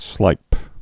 (slīp)